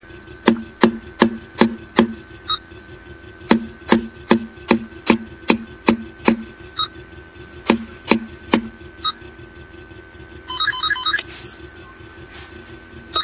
Hear the KnockLock activate when the code is CORRECT: WAV FILE (210K) OR Real Audio (32K) (correct code is 5 - 8 - 3 ) Hear the KnockLock when the WRONG code is entered: WAV FILE (114K) OR Real Audio (18K) [BACK]